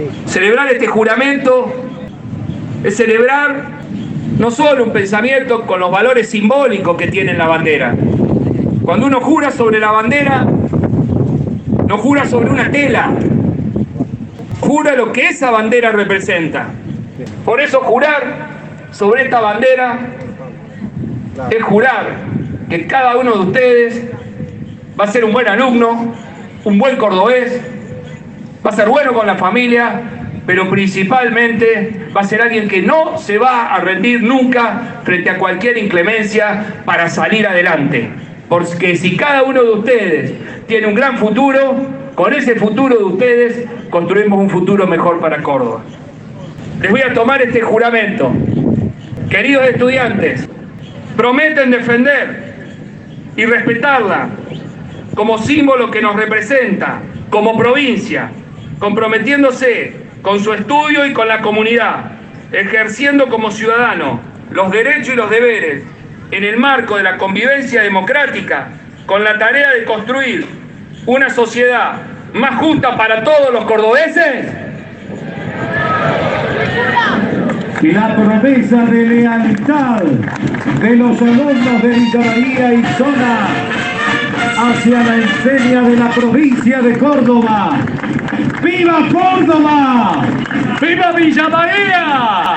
El gobernador Martín Llaryora llegó a Villa María para encabezar el acto de jura a la bandera de Córdoba y también para poner en marcha la Guardia Urbana local.
Posterior a la presentación de la Guardia Urbana, 1.100 alumnos de cuarto año de 22 instituciones de nivel medio realizaron la Promesa de Lealtad.
LLARYORA-JURA-A-LA-BANDERA.ogg